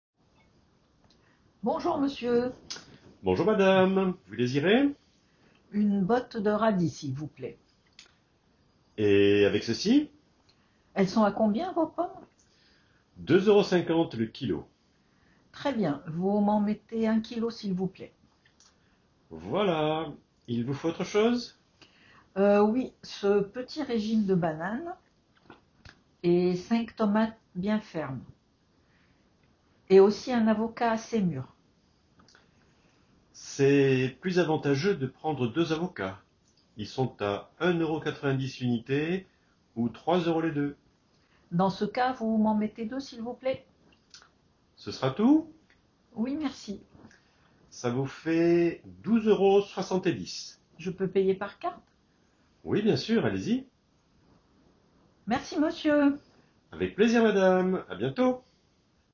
Enregistrement: acheter chez le primeur Catégorie : semi-authentique Type : enregistrement audio Tags : commerce ‣ primeur ‣ achat ‣ alimentation ‣ Télécharger la ressource.